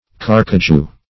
Carcajou \Car"ca*jou\ (k[aum]r"k[.a]*j[=oo]), n. [Probably a